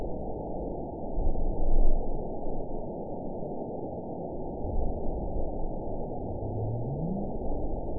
event 912581 date 03/29/22 time 21:11:03 GMT (3 years, 1 month ago) score 9.63 location TSS-AB01 detected by nrw target species NRW annotations +NRW Spectrogram: Frequency (kHz) vs. Time (s) audio not available .wav